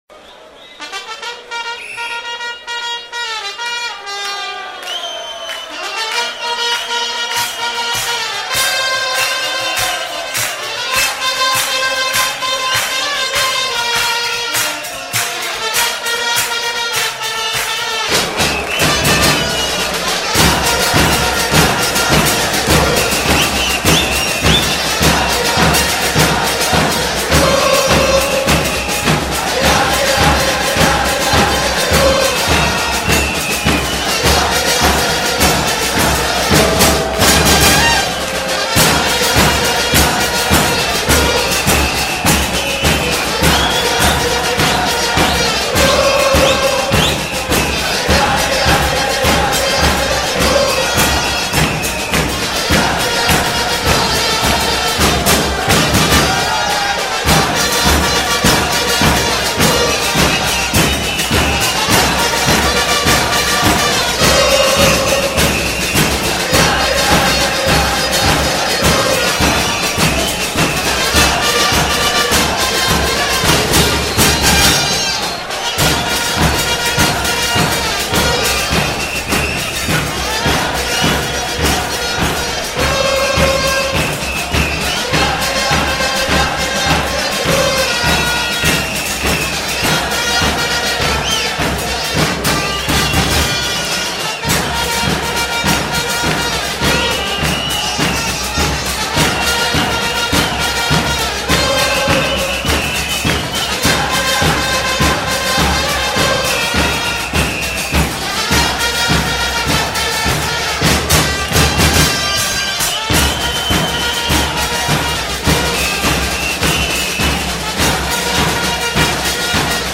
Zde si můžete stáhnout 4 chorály Bocy (3 z nich natočeny přímo v kotli La Doce u kapely):
Chorál 2 (2,40 MB)